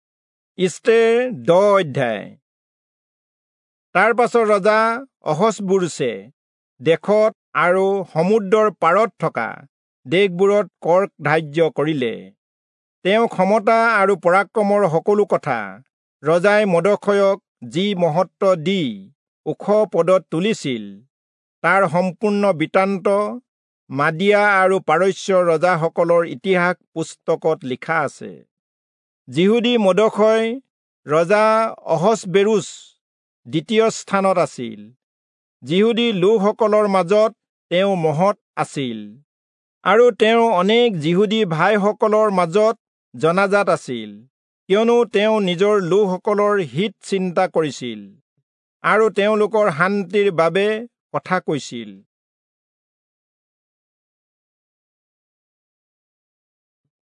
Assamese Audio Bible - Esther 5 in Web bible version